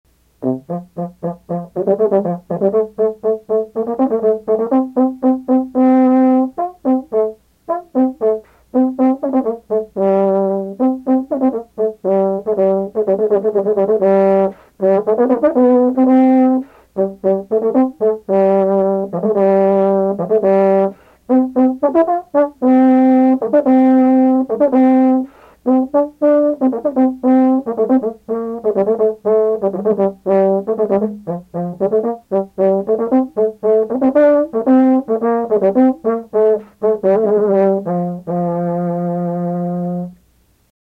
Douglas Yeo plays the serpent
Handel - La Rejouissance (from "Music for the Royal Fireworks"). Recorded at WBUR Radio Studio, September 28, 1999. Douglas Yeo, serpent (Baudouin).